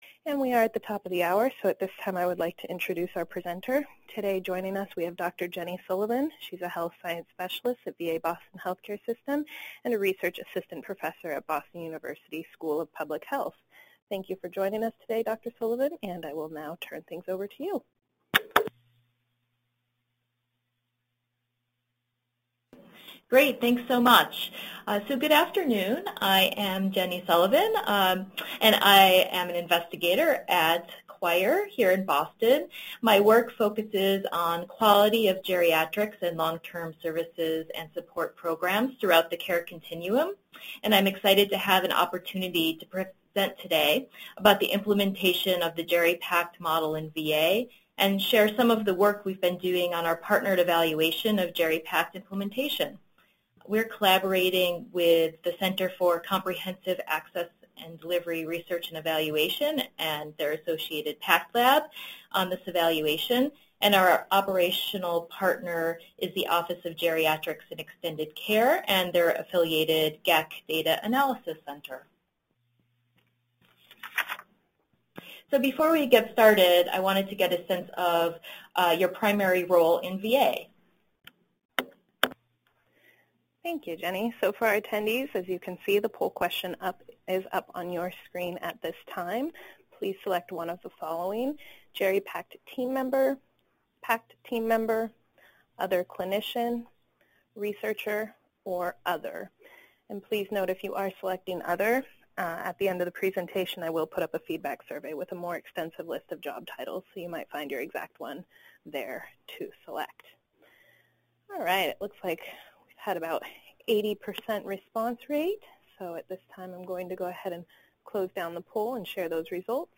Description: In this cyber seminar, we will review what the GeriPACT model is; describe how GeriPACTs are structured; and outline factors affecting implementation of the model.